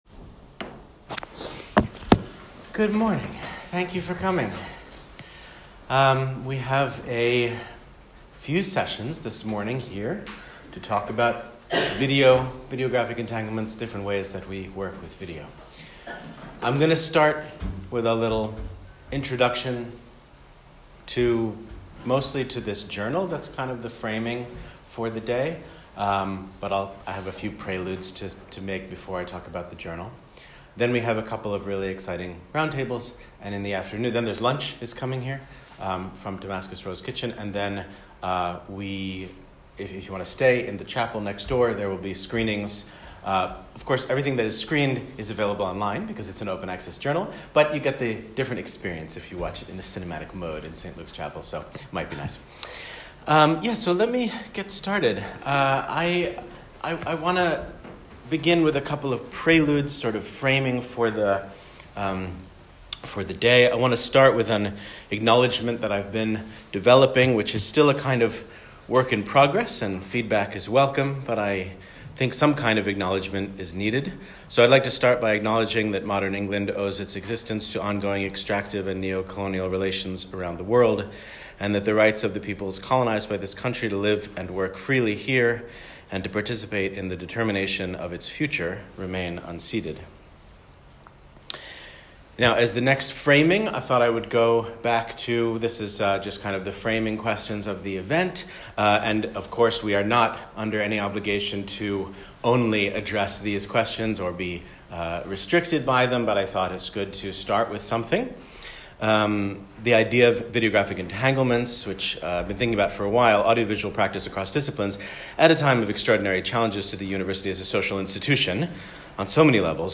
Introduction to Journal of Embodied Research